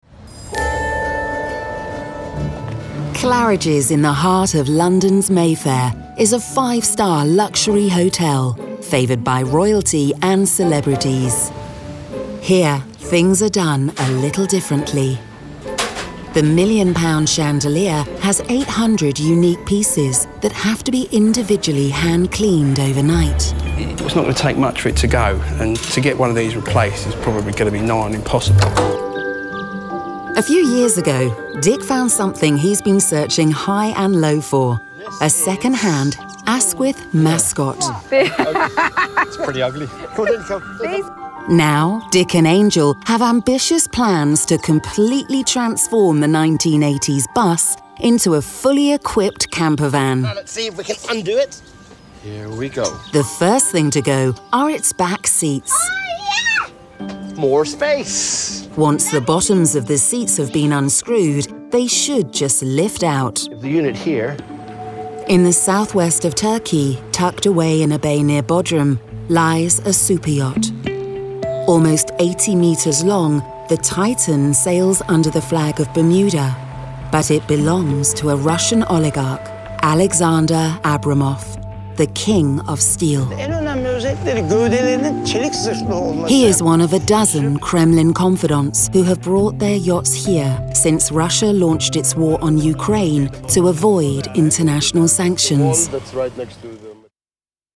Multi-Nominated Professional British Voiceover. Clear, Contemporary, Confident.
Documentary Reel
Natural RP accent, can also voice Neutral/International and character.
Broadcast-ready home studio working with a Neumann TLM 103 mic.